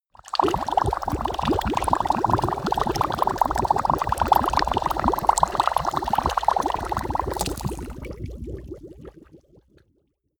Gemafreie Sounds: Flüssigkeiten